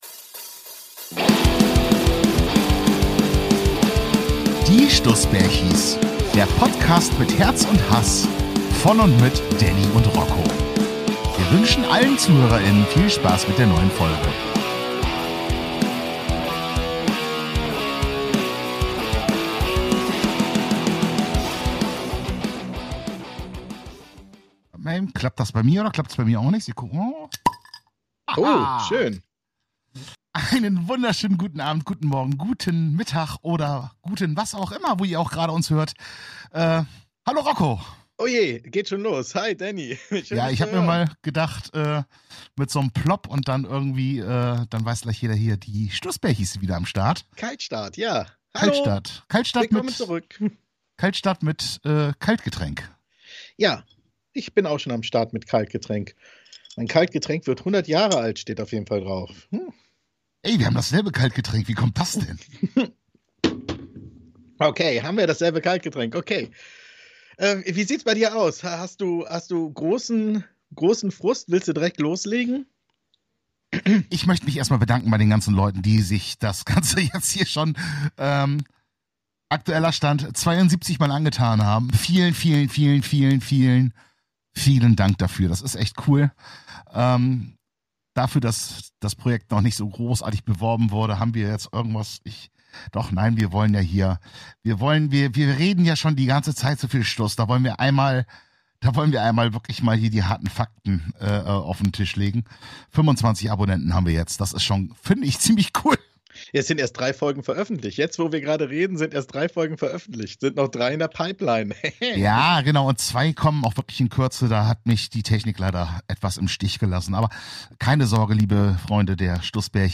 Die Bärchies sind wieder zurück im Studio!